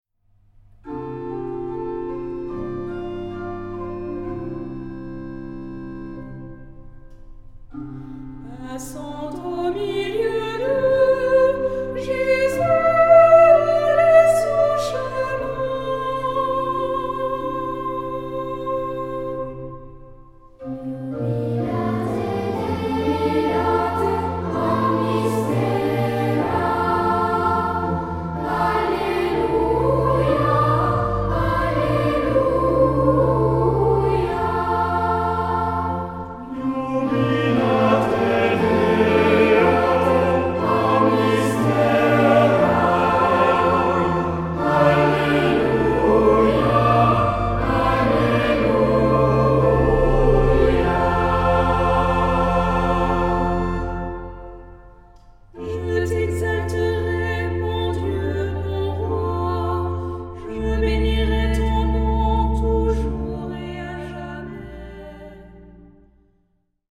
SATB (4 voces Coro mixto) ; Partitura general.
Salmodia.